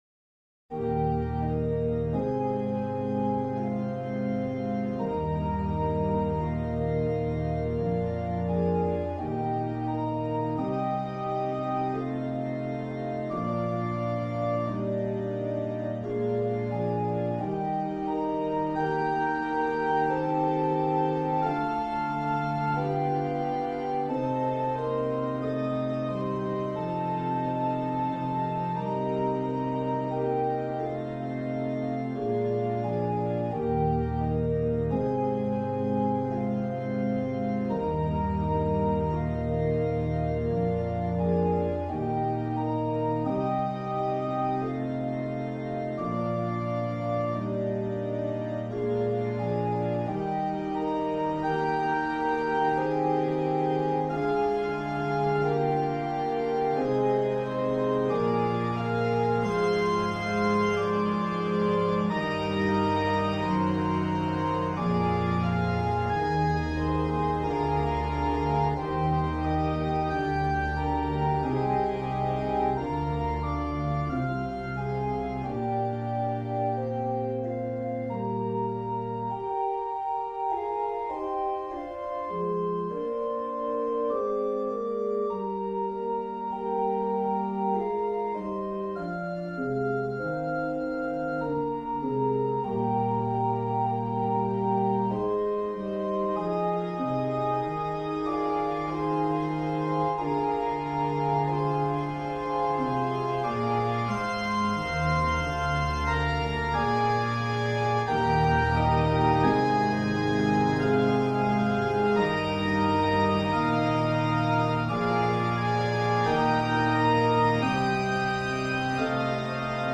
edward-elgar-enigma-variations-nimrod-for-organ.mp3